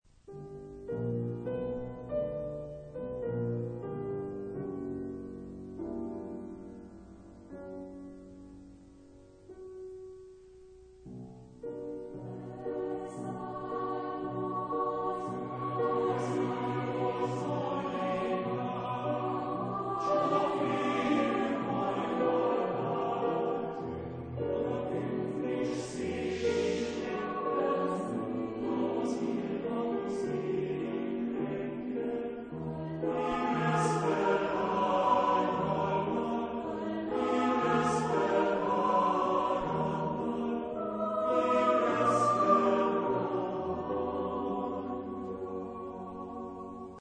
Epoque: 20th century  (1950-1959)
Genre-Style-Form: Secular ; Lyrical ; Choir
Type of Choir: SATB  (4 mixed voices )
Instruments: Piano (1)
Tonality: modal